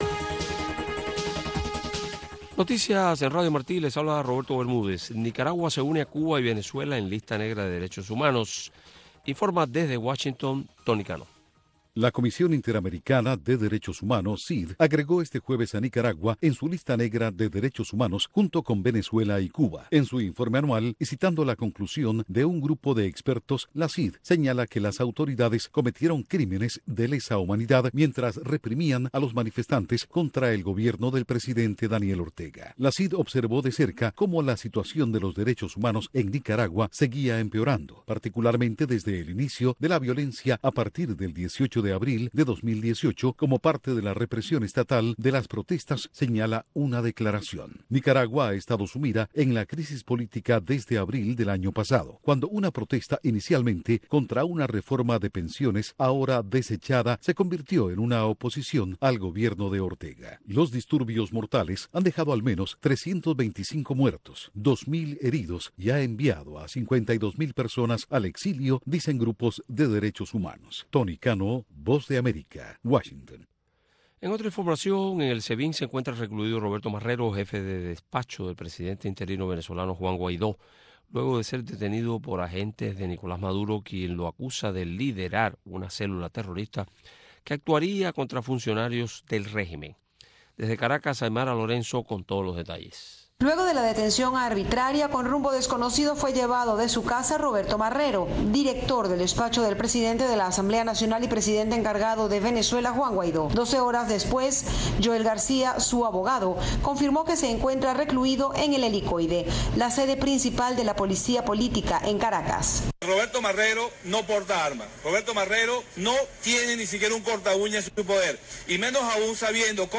Hoy en “Ventana a Miami”, continuamos conversando con el realizador Orlando Jiménez Leal justo cuando mañana sábado se vuelve a exhibir una versión mejorada técnicamente de " Conducta Impropia"